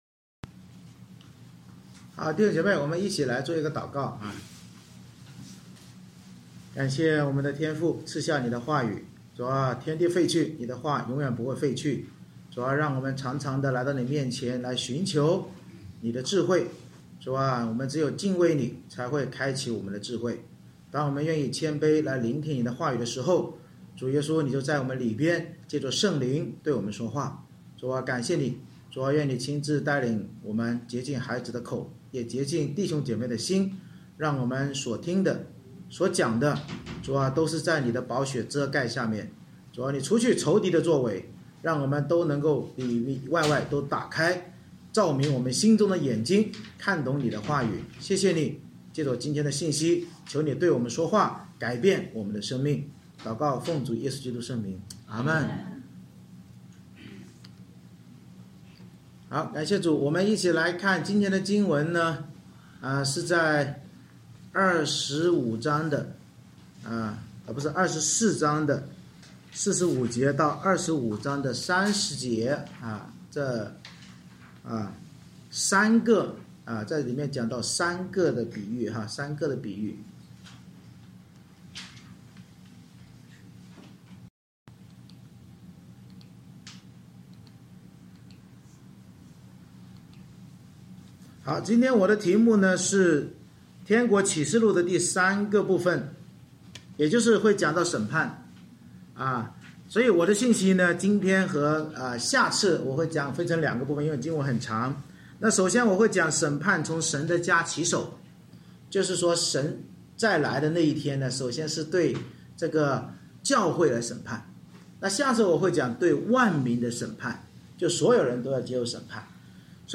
马太福音24:45-25:30 Service Type: 主日崇拜 耶稣通过天国启示录末日审判的比喻，警告我们要做神百般恩赐的好管家，忠心殷勤服事主，只有智慧警醒的真门徒才能在基督再临审判教会那日不被拒于天国门外。